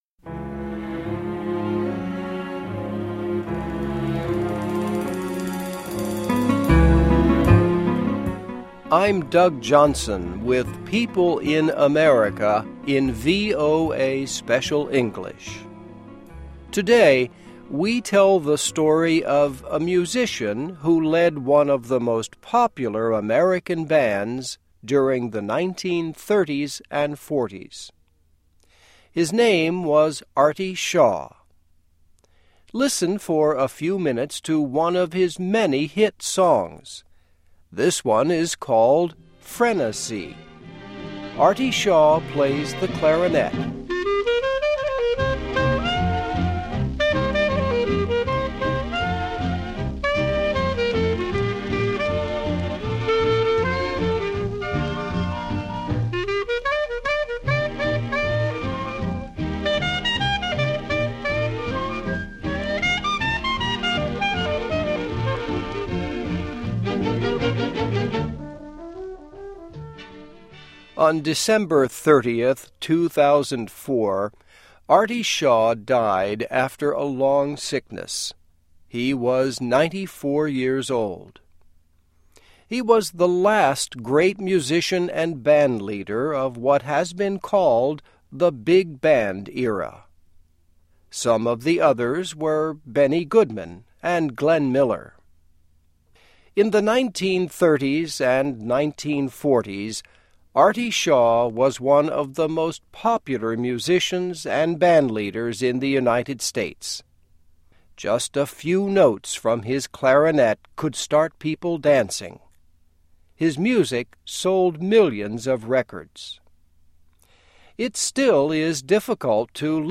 Listen for a few minutes to one of his many hit songs.